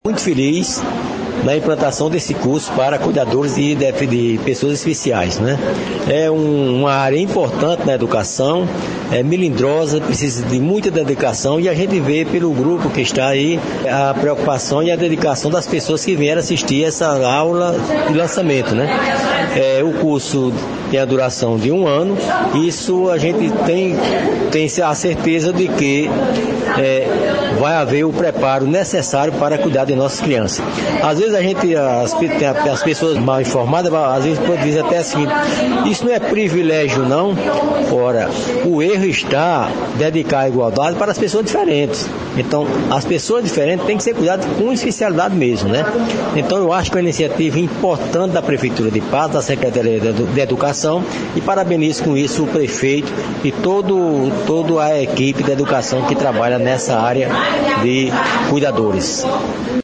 Fala do vice-prefeito, Bonifácio Rocha –